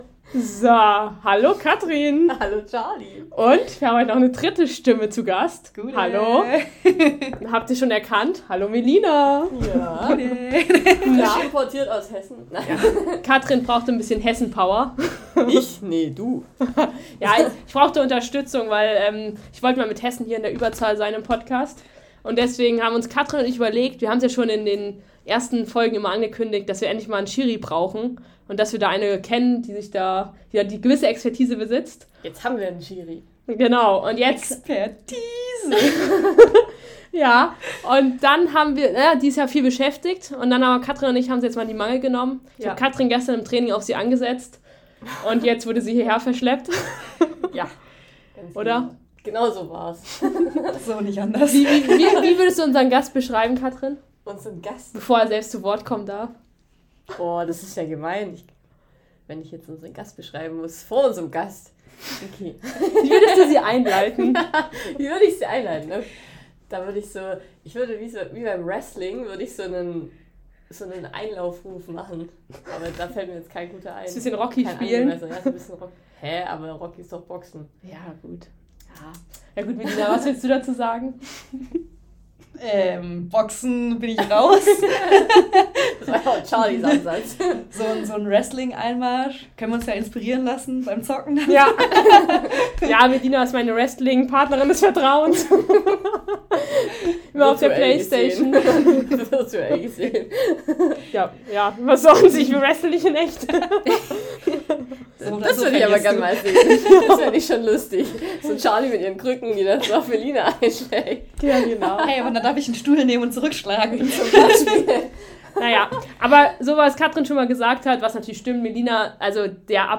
Wir haben unseren Gast mit Fragen gelöchert und am Ende gibts noch ein paar Witze, also wer lachen will - reinhören!